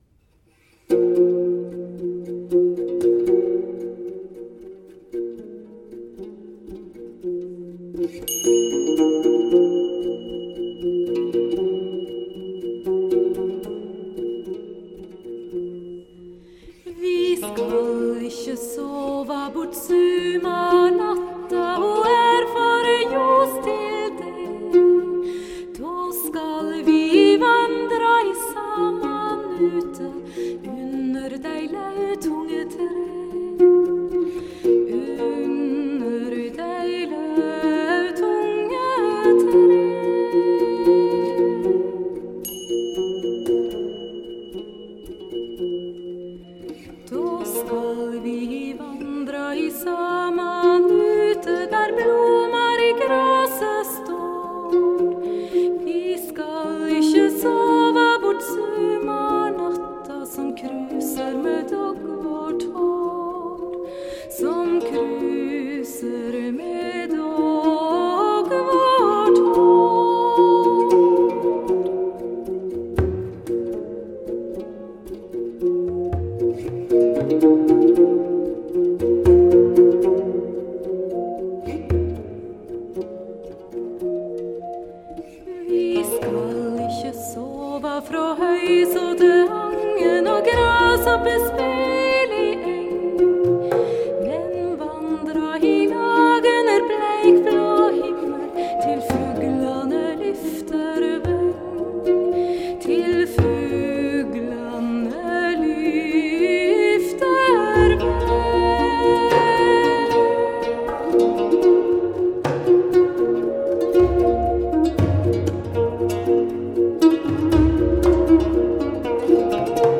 巧妙地將東方冥想音樂與西方古典聲樂融合在一起
呈現出一種格外輕鬆、悠然的舒適氛圍
濃厚的New-Age和跨界氣息
錄音地點選在奧斯陸近郊的一座古老大教堂，偌大的空間音場表現